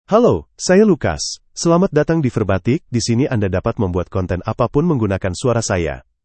LucasMale Indonesian AI voice
Lucas is a male AI voice for Indonesian (Indonesia).
Voice sample
Listen to Lucas's male Indonesian voice.
Male
Lucas delivers clear pronunciation with authentic Indonesia Indonesian intonation, making your content sound professionally produced.